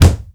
punch_low_deep_impact_03.wav